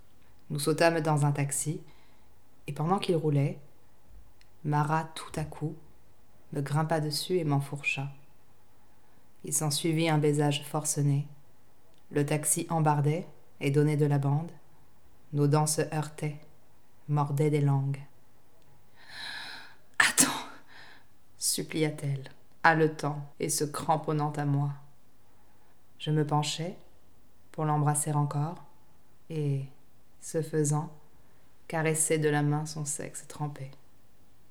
Voix Roman Erotique Fr
20 - 45 ans - Mezzo-soprano